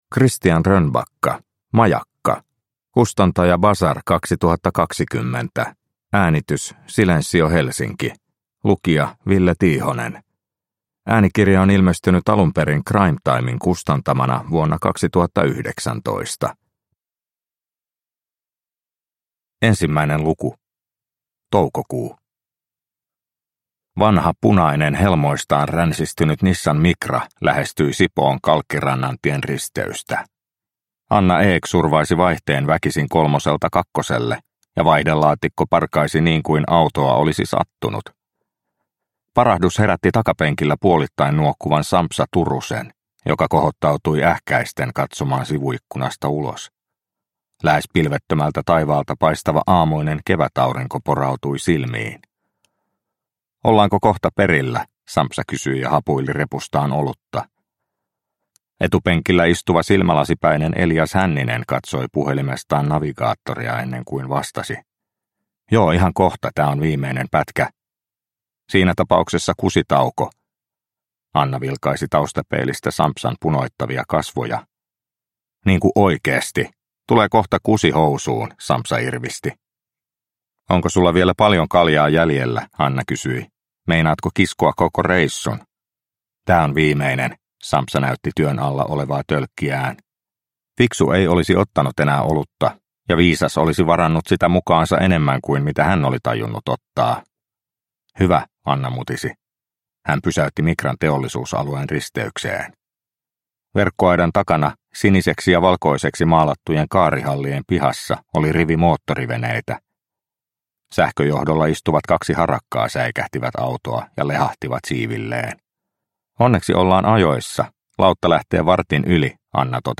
Majakka – Ljudbok – Laddas ner